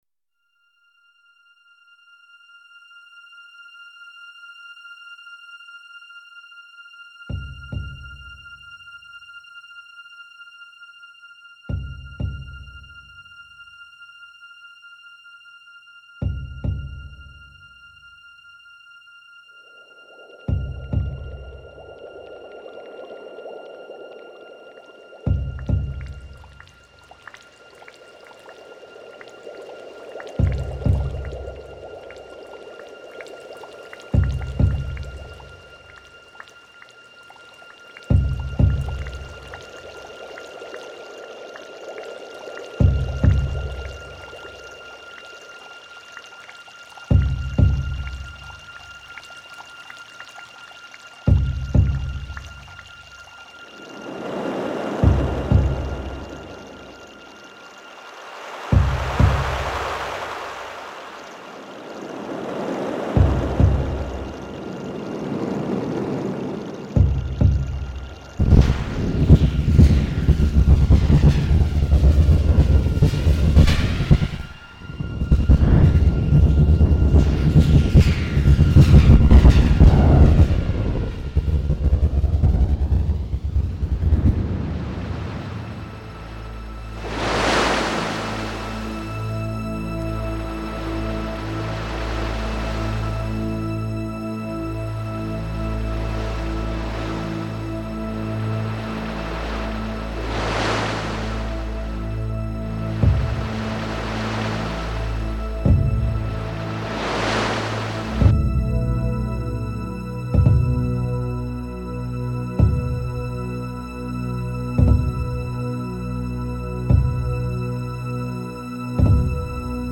音乐使我放松，使人有飘起来的感觉。